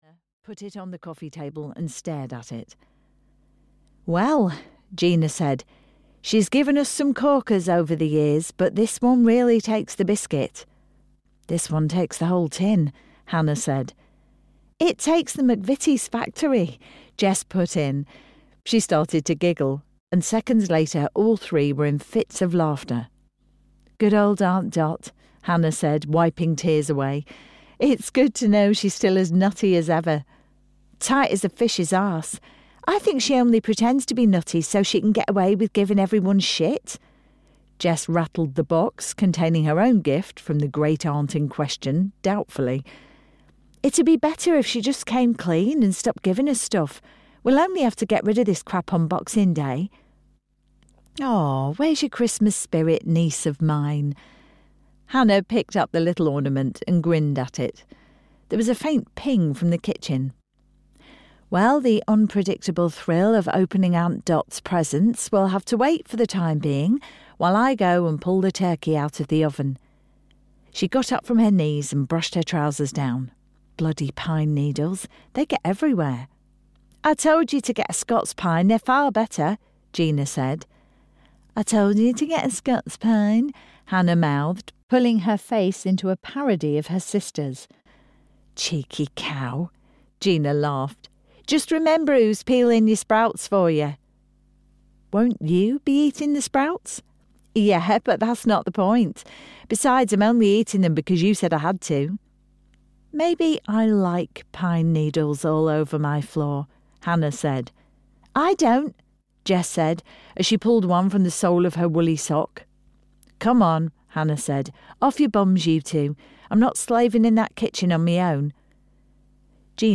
Once Upon a Winter (EN) audiokniha
Ukázka z knihy